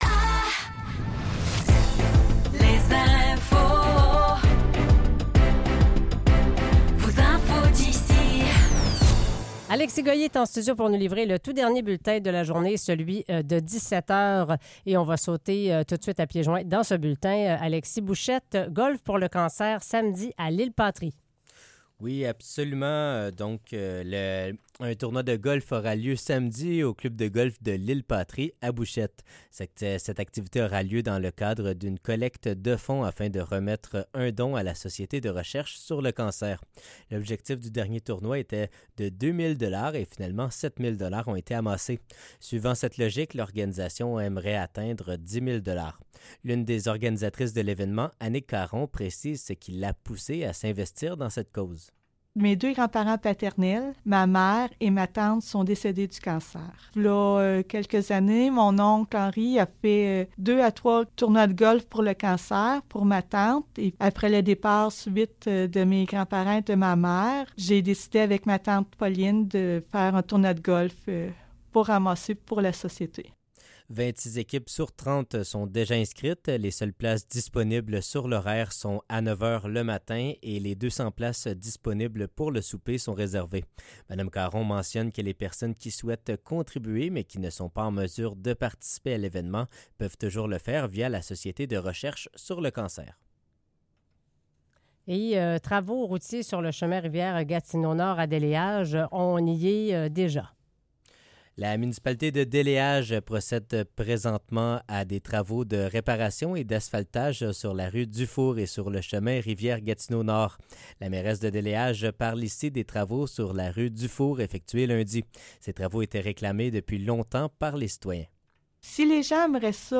Nouvelles locales - 7 août 2024 - 17 h